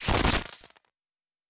pgs/Assets/Audio/Sci-Fi Sounds/Electric/Glitch 2_05.wav at 7452e70b8c5ad2f7daae623e1a952eb18c9caab4
Glitch 2_05.wav